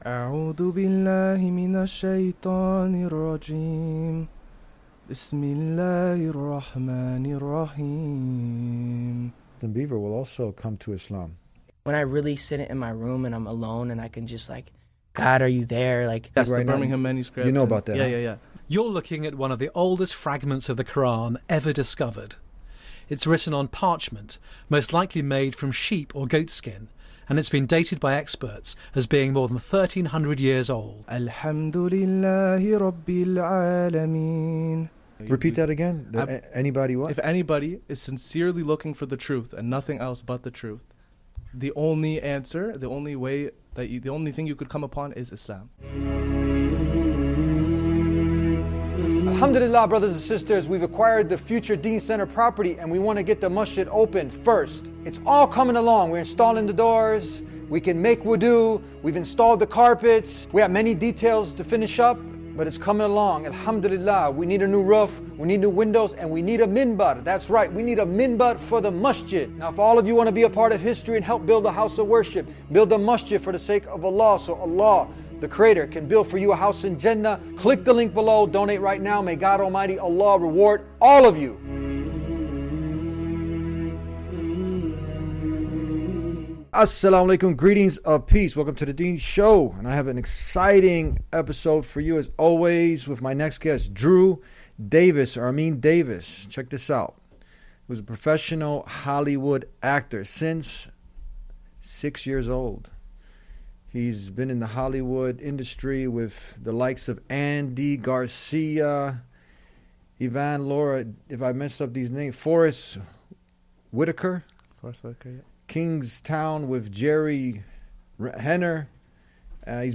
LISTEN TO THIS Hollywood Actor Recite the Quran after HE TRIED SO HARD TO DISPROVE ISLAM